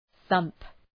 Προφορά
{ɵʌmp}